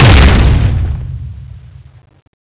exp.wav